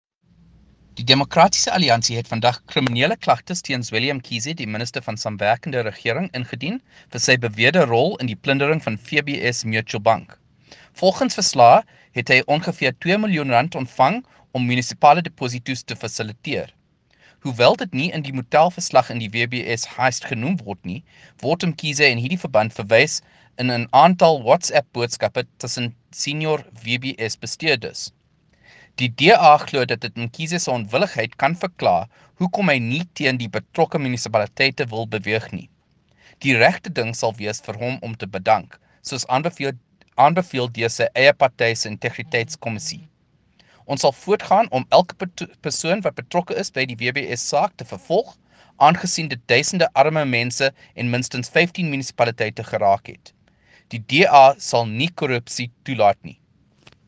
The statement below follows DA Shadow Minister of Cooperative Governance and Traditional Affairs (COGTA) Kevin Mileham MP laying criminal charges against COGTA Minister Zweli Mkhize.
Mileham_Mkhize_Afrikaans.mp3